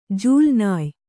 ♪ jūlnāy